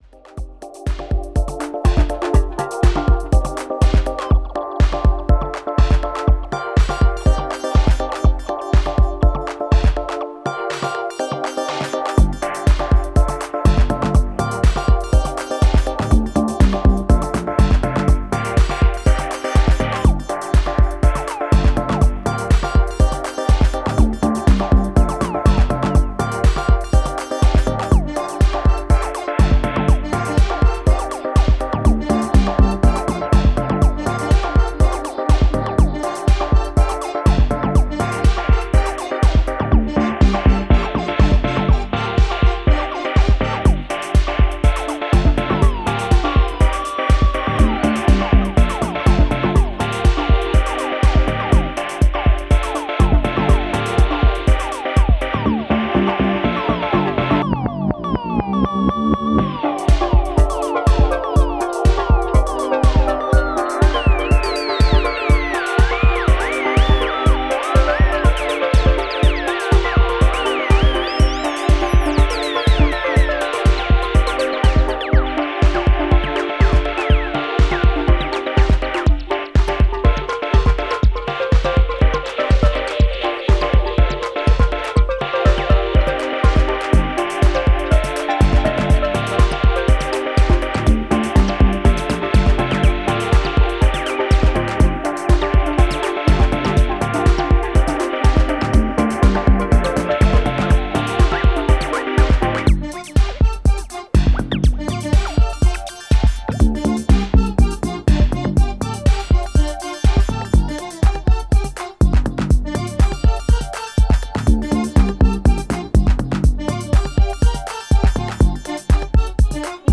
US/Europe のオイシイところを上手く取り入れたアンダーグラウンドなクラブ・トラックス。